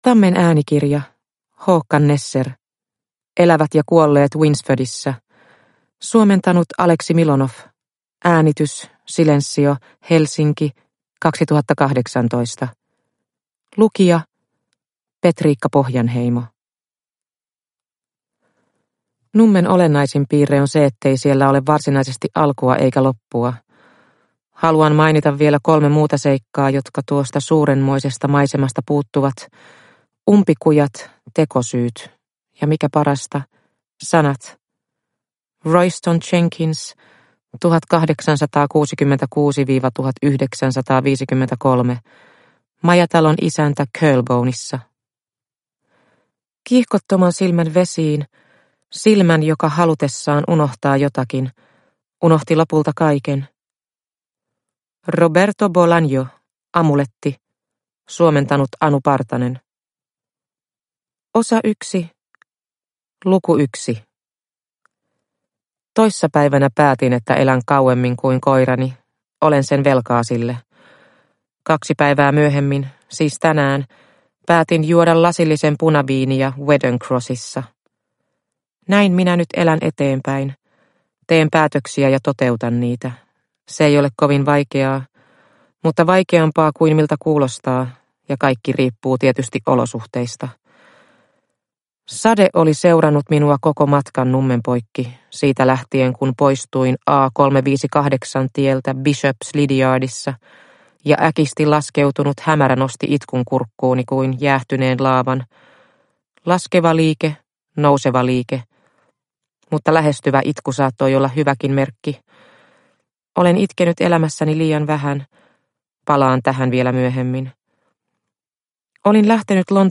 Elävät ja kuolleet Winsfordissa – Ljudbok – Laddas ner